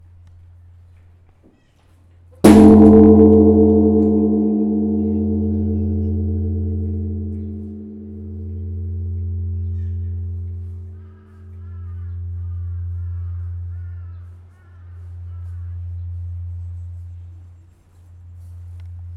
huge religious gong from zen buddhism
bang bell blacksmith buddhism clang ding gong hammer sound effect free sound royalty free Memes